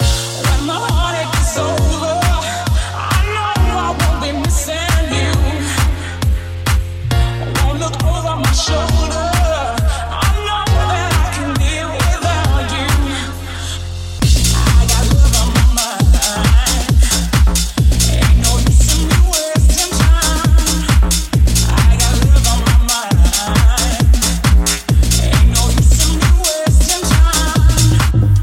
Genere: pop, house, deep, club, edm, remix